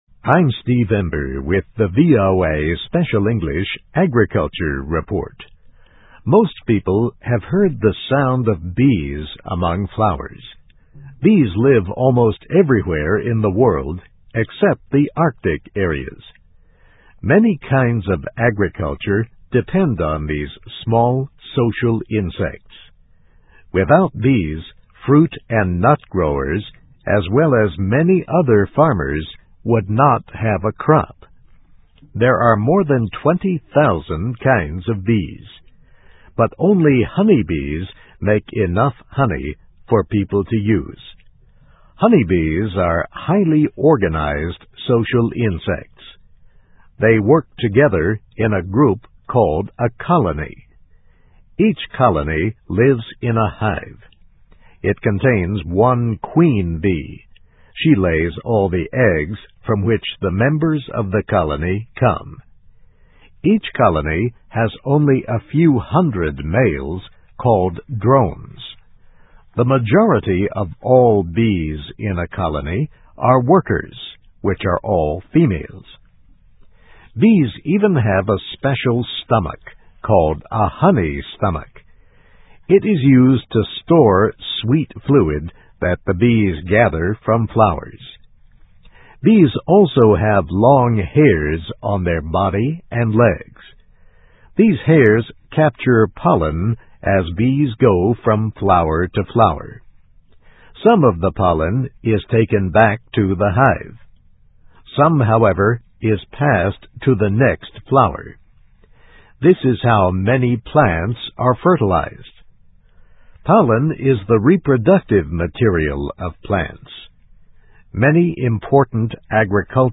Animals: Bees and Beekeeping, Part 1 (VOA Special English 2005-12-19)